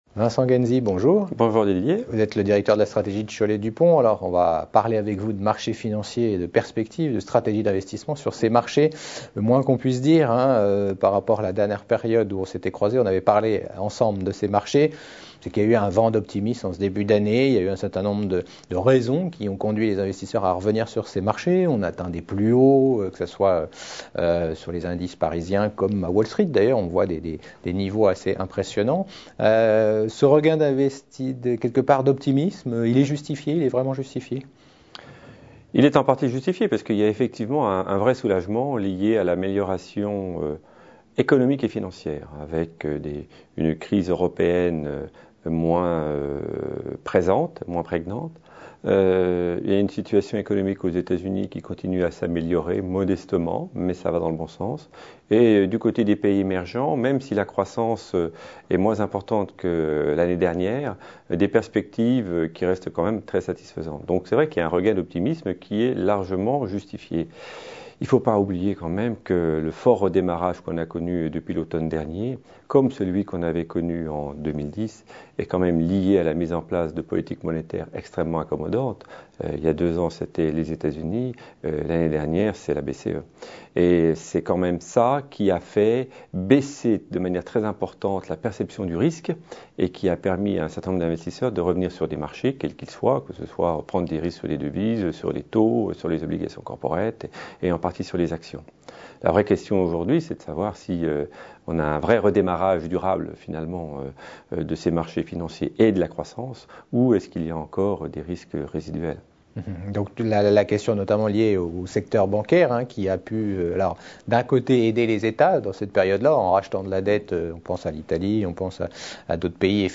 Interview du 16 mars 2012.